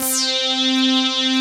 303STABBR4.wav